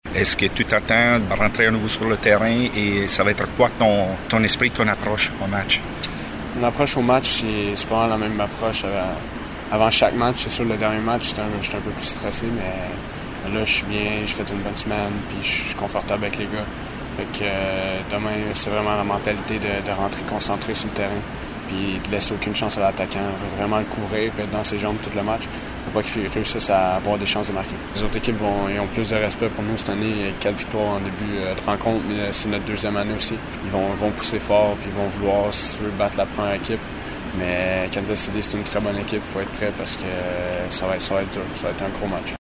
Le interviste dopo la rifinitura di questa mattina prima della partenza per il Kansas: